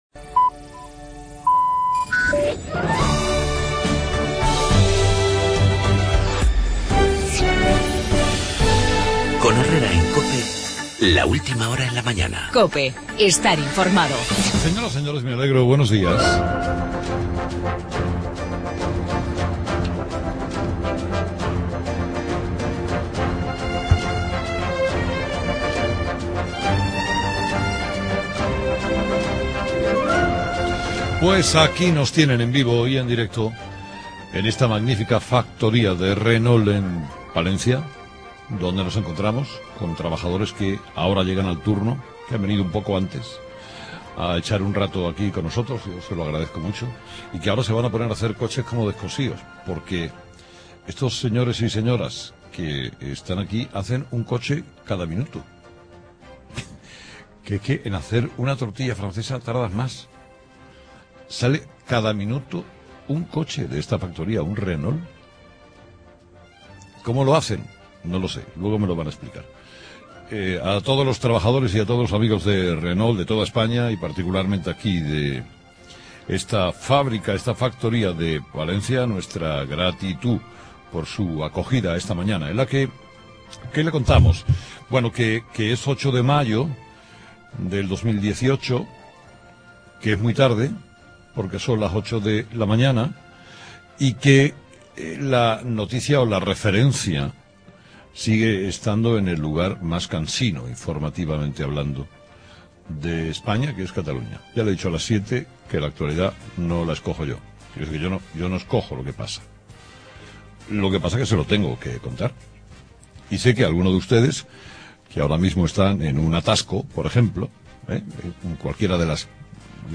Desde la Renault en Palencia